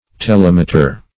Telemeter \Te*lem"e*ter\, n. [Gr.